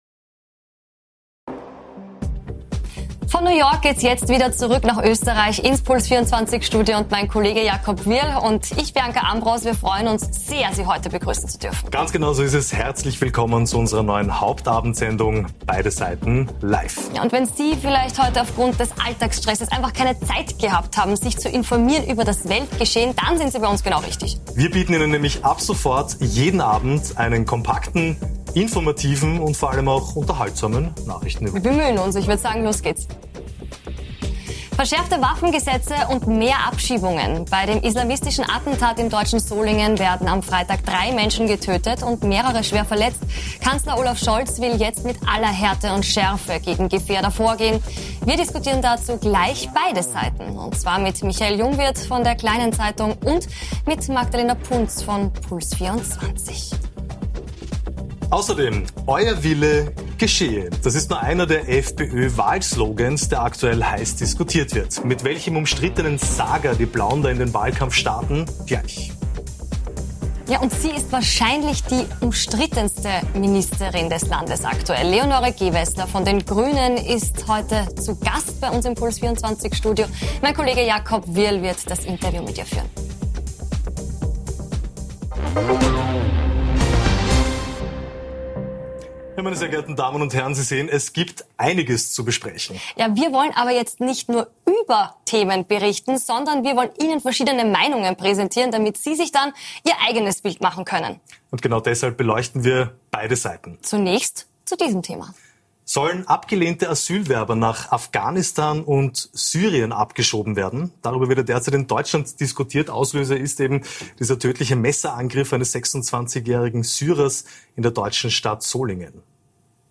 Zu Gast: Umweltministerin Leonore Gewessler (Die Grünen) ~ Beide Seiten Live Podcast